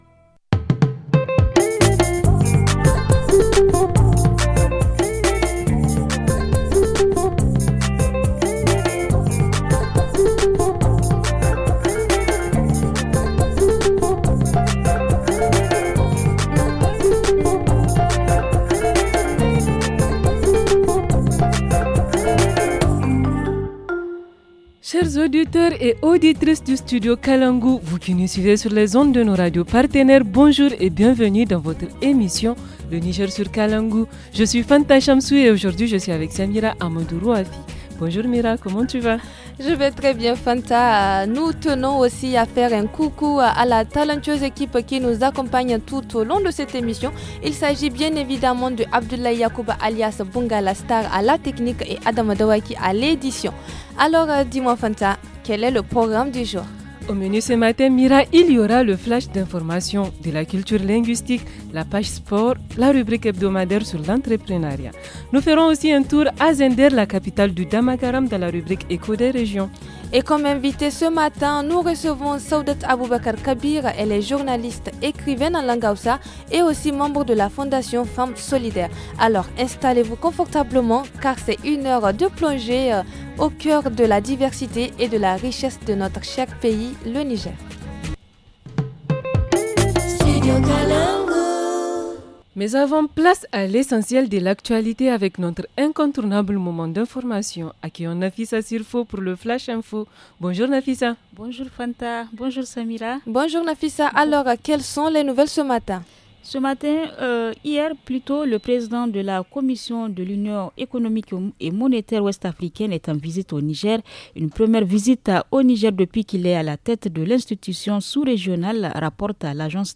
-Reportage Région : Reportage sur l’abattoir frigorifique de Zinder ;